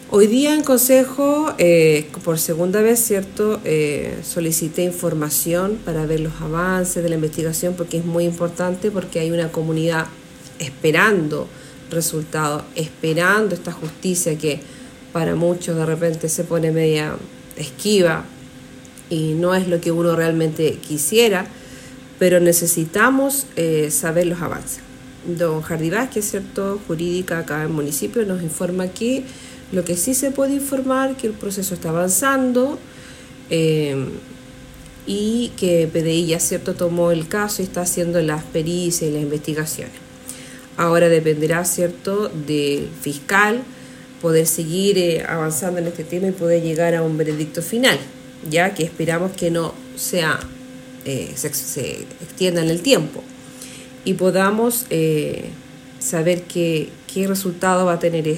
Esta situación fue consultada durante la última sesión del Concejo Municipal, para conocer los avances.
La Concejala Cecilia Canales señaló que existe una comunidad organizada, quienes están esperando una resolución por parte de la justicia, cuya investigación se está realizando.